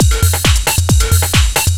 DS 135-BPM A1.wav